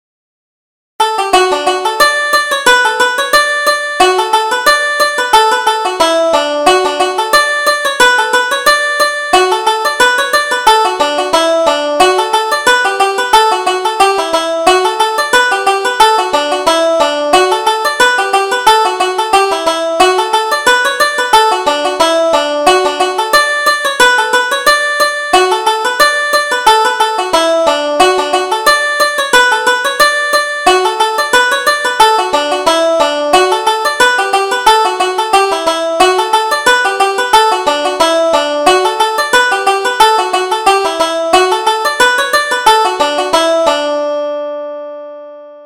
Reel: Kitty Got a Clinking Coming from the Races